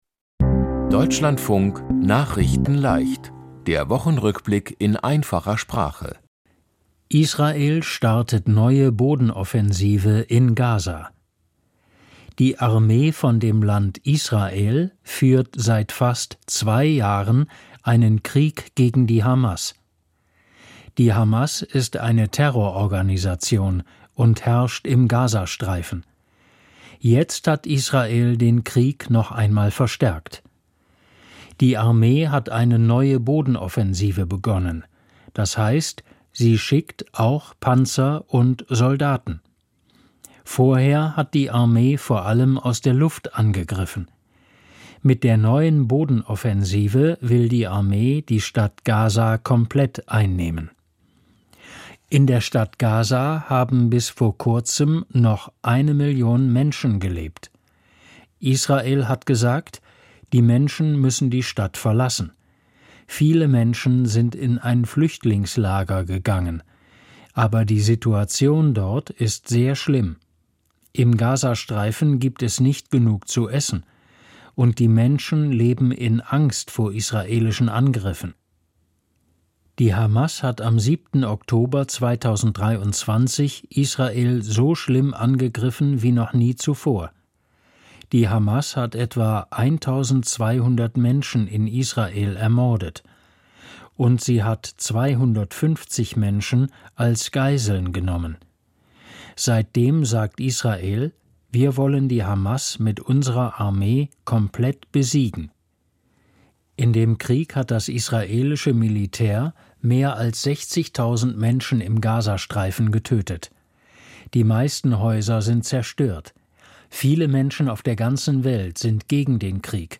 Die Themen diese Woche: Israel startet neue Boden-Offensive in Gaza, Bundestag beschließt Haushalt für 2025, Deutschland-Ticket wird teurer, Ozon-Loch wird immer kleiner, Deutsche Basketballer gewinnen EM-Finale und Filme-Macher Robert Redford ist tot. nachrichtenleicht - der Wochenrückblick in einfacher Sprache.